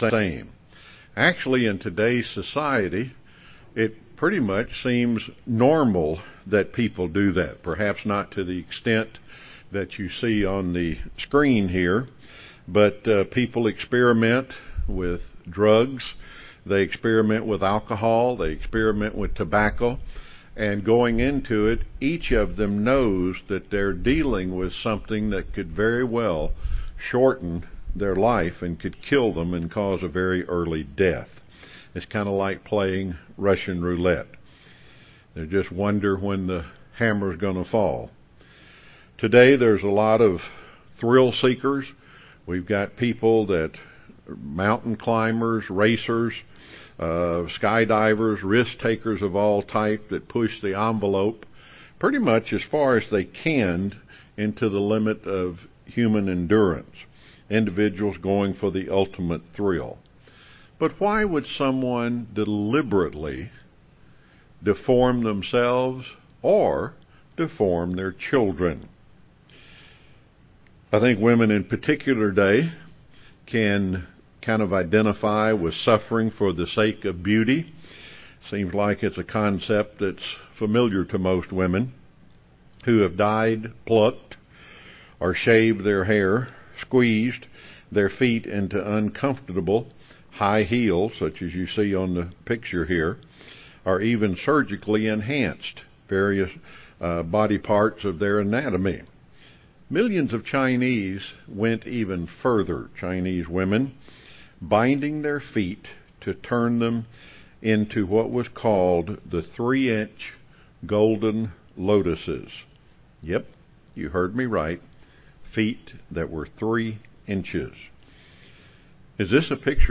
This sermon compares the ancient Chinese custom of binding the feet to the way sin puts a binding on our heart.
Given in Rome, GA
UCG Sermon Studying the bible?